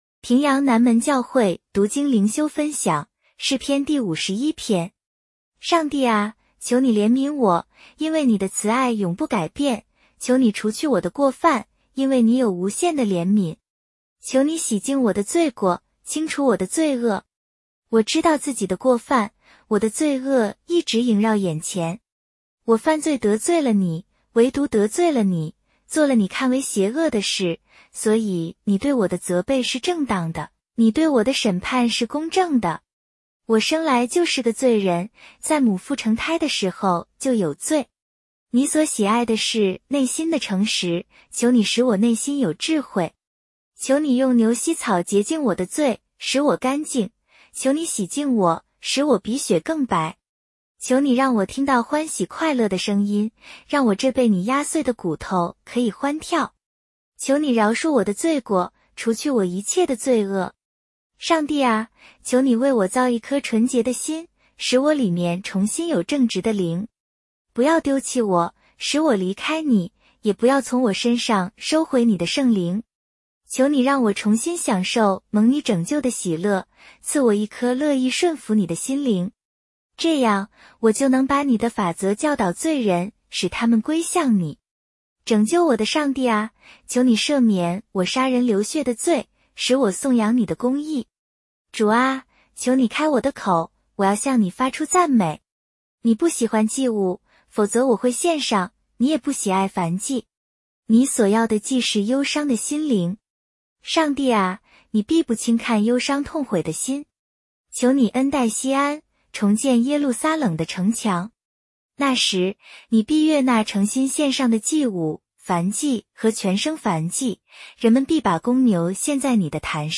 普通话朗读——诗51